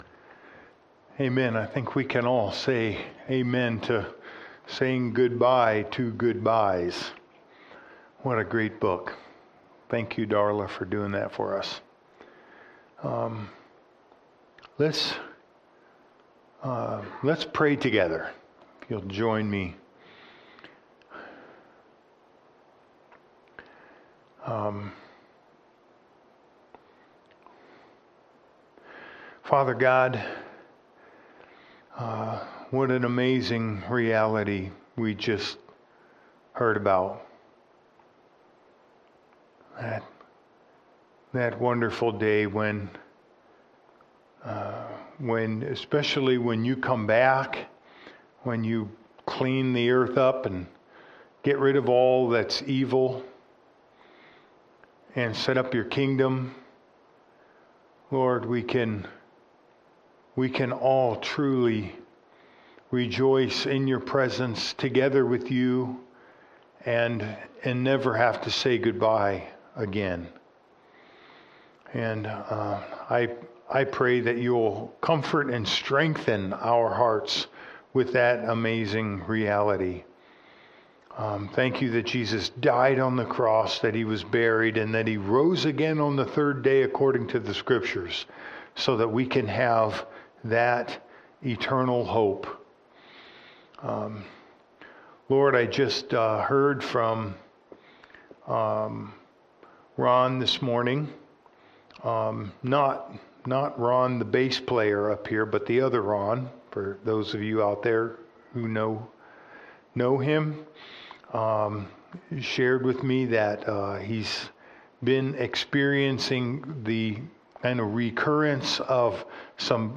Gethsemane Covenant Church Archived Sermons